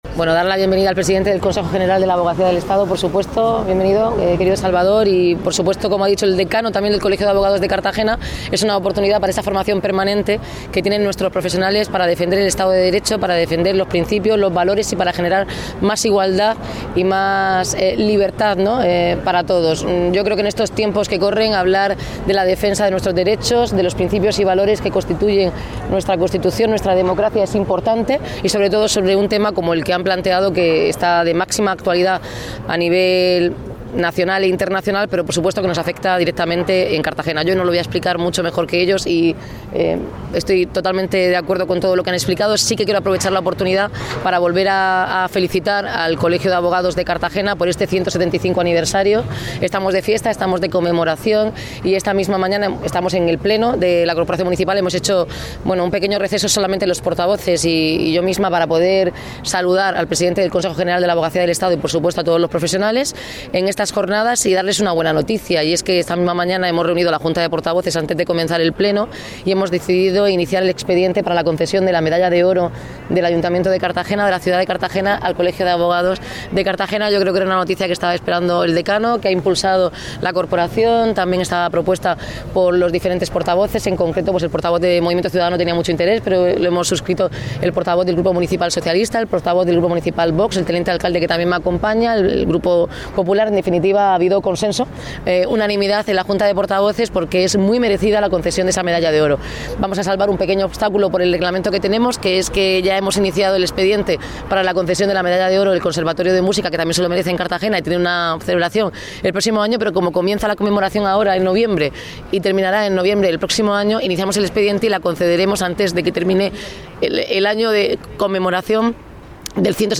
Enlace a Declaraciones de Noelia Arroyo
La alcaldesa ha hecho el anuncio en la inauguración de las XXXII Jornadas de Extranjería y Protección Internacional de la Abogacía Española que ha contado con la asistencia del presidente del Consejo General de la Abogacía del Estado y han congregado en el CIM a cientos de letrados y expertos en la materia